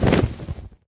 dragon_wings2.ogg